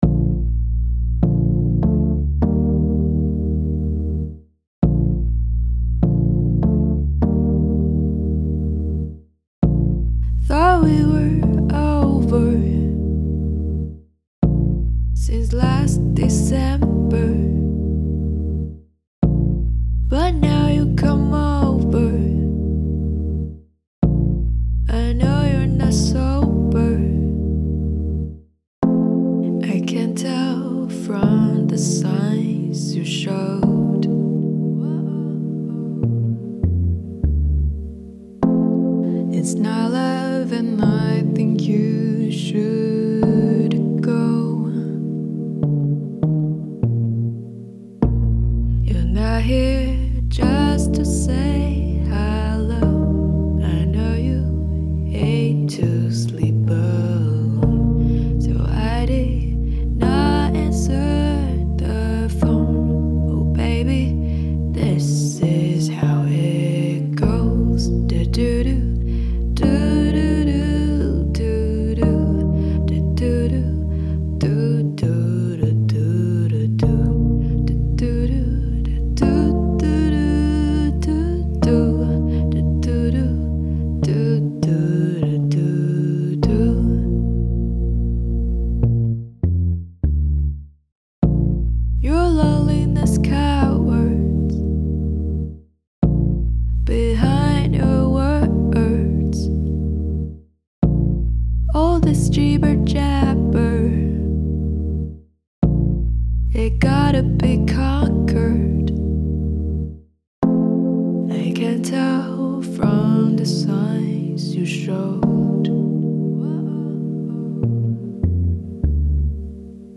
Genre: Pop
Version: Demo